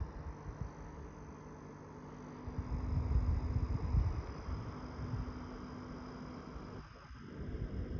This sound is coming from the device itself, not from speakers. When in operate mode it gets louder but still its not so loud but it is noticeable sitting close to it.
I tried moving my phone to find the best place I can capture it thats why it goes up and down in volume as its constantly coming from the device at the same volume. seems like a physical vibration or something?